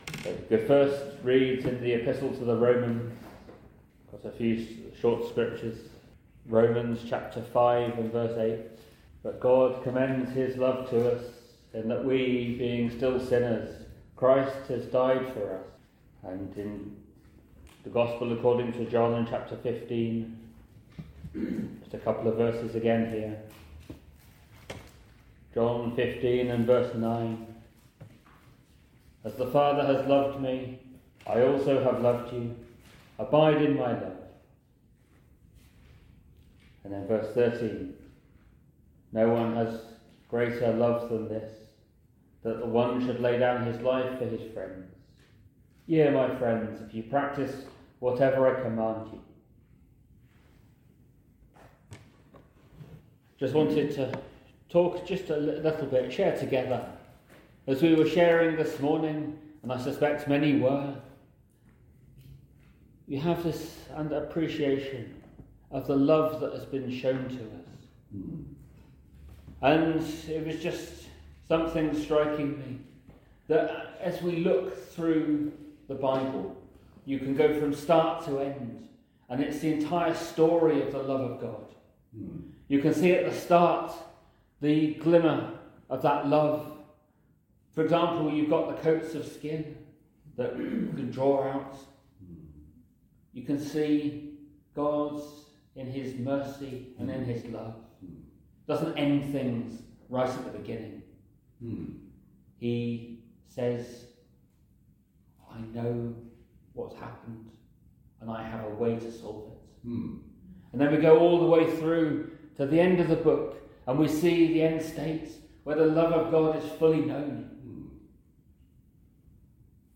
The sermon challenges us to embrace this love fully and live in response to the sacrifice made for us.